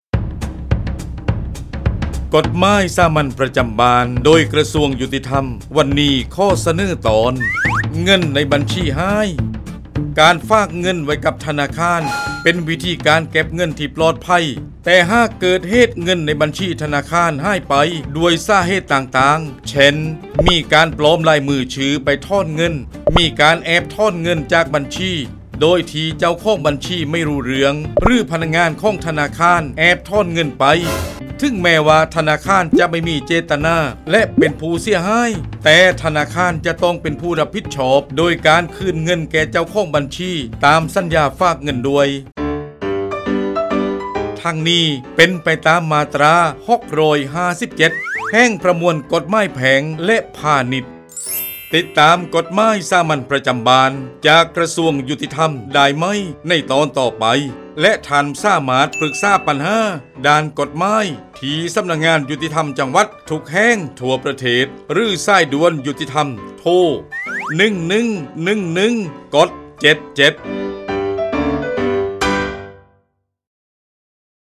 ชื่อเรื่อง : กฎหมายสามัญประจำบ้าน ฉบับภาษาท้องถิ่น ภาคใต้ ตอนเงินในบัญชีหาย
ลักษณะของสื่อ :   บรรยาย, คลิปเสียง